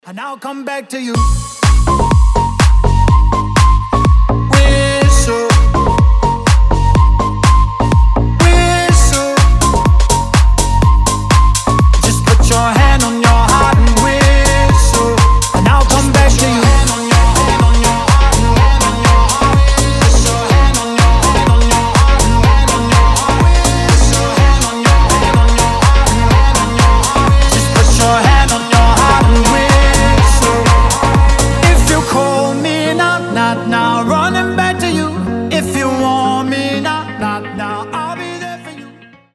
• Качество: 320, Stereo
позитивные
свист
house